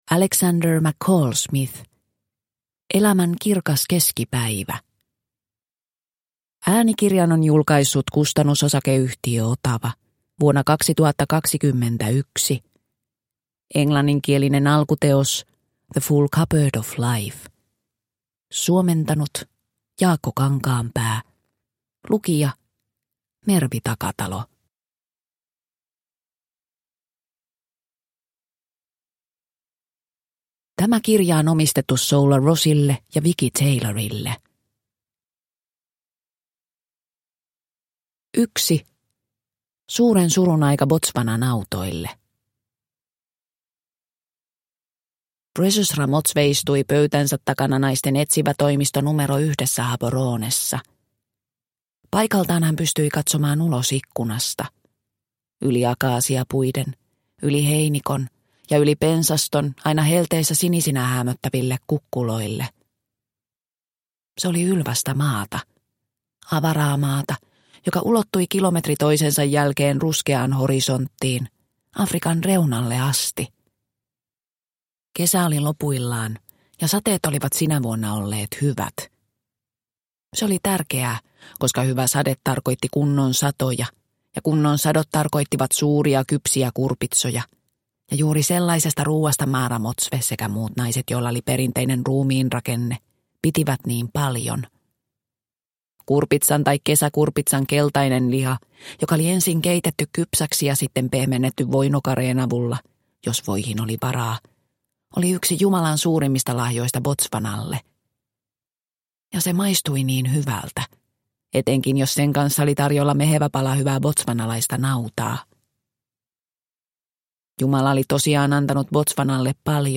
Elämän kirkas keskipäivä – Ljudbok – Laddas ner